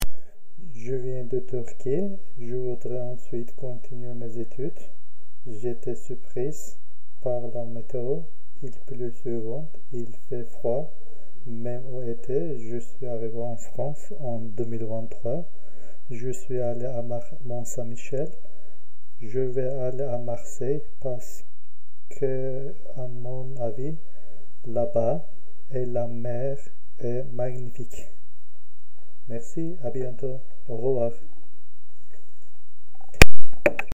Cabine de témoignages